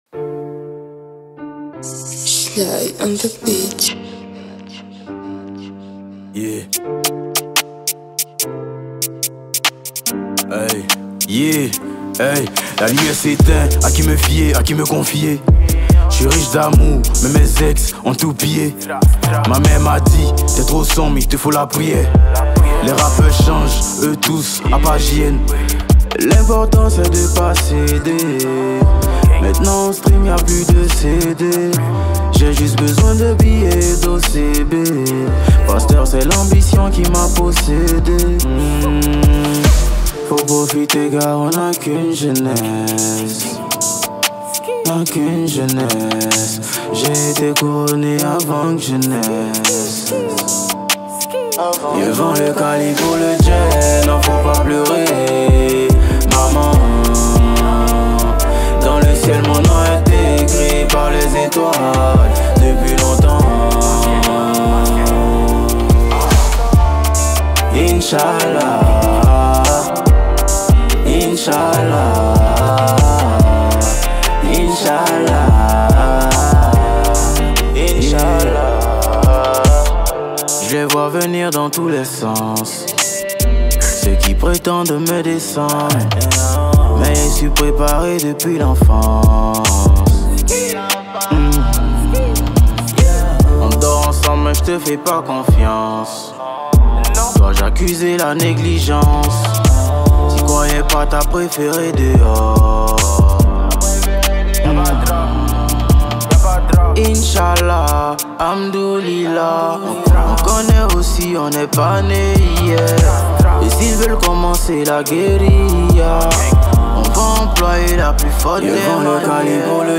| Rap ivoire